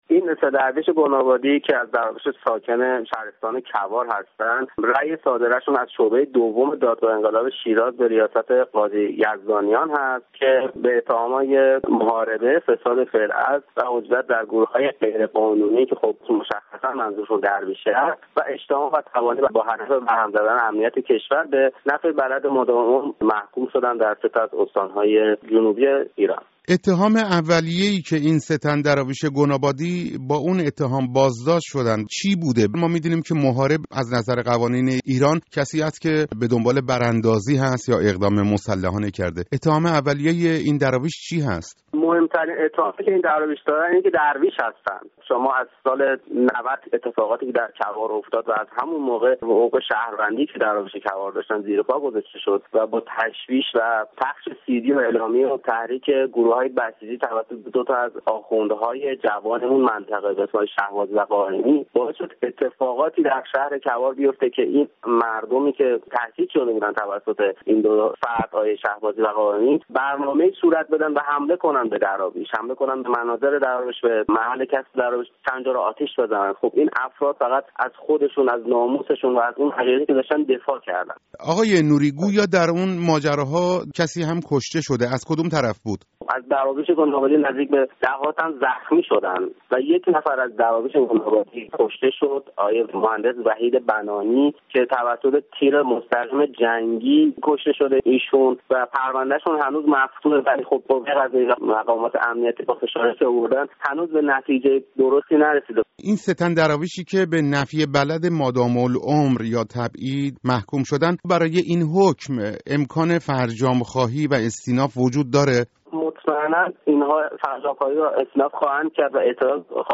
گفتگوی